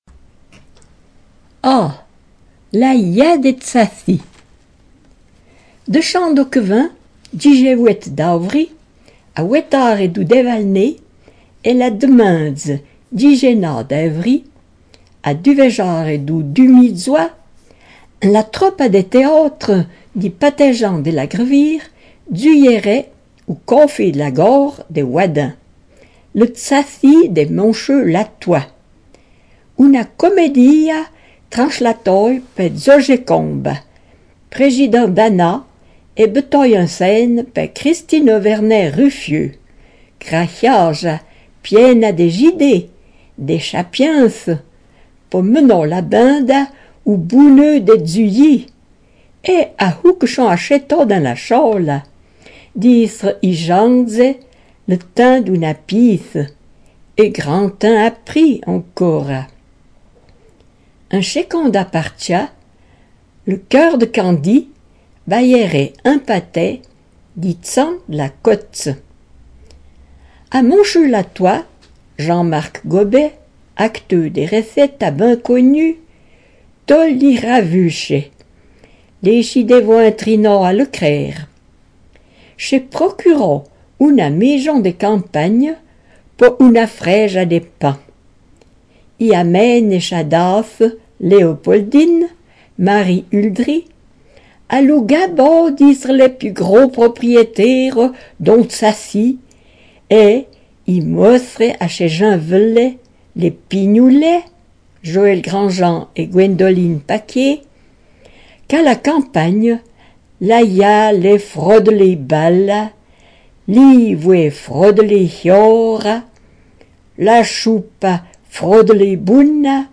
En patois